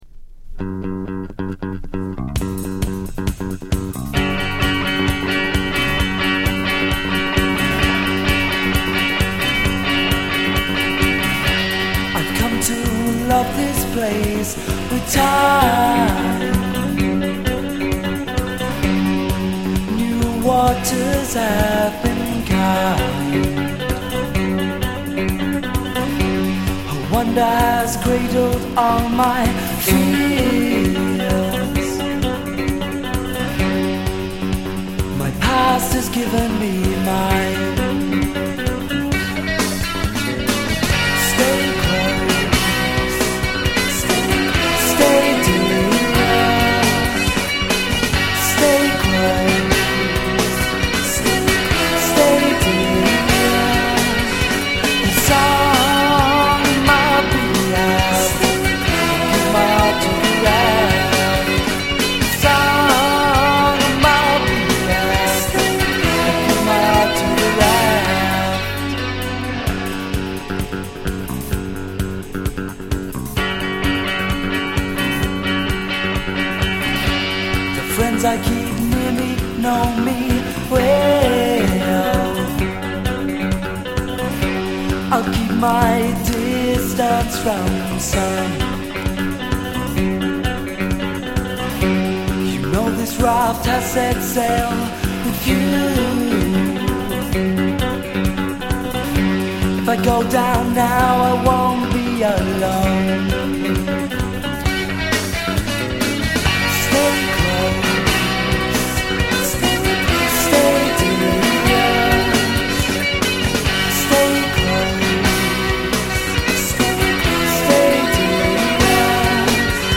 bass
drums
with fantastic basslines and breezy vocals